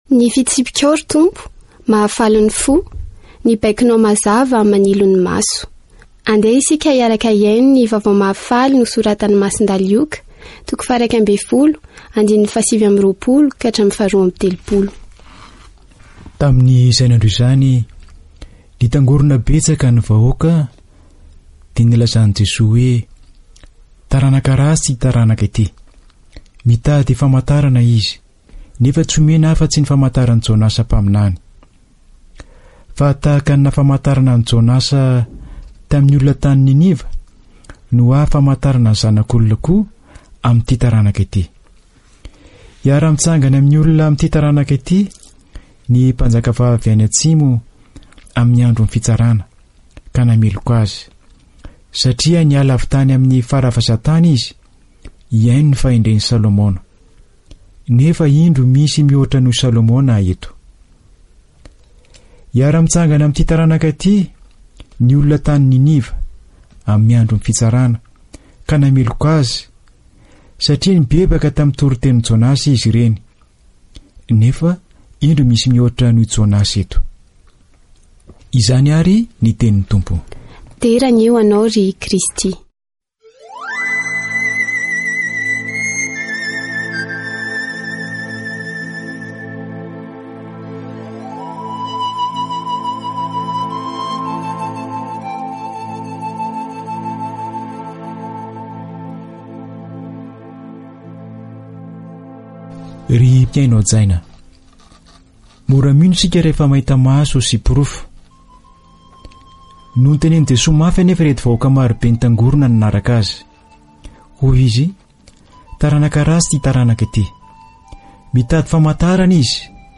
Catégorie : Évangile